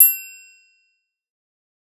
～【効果音】～
ち～ん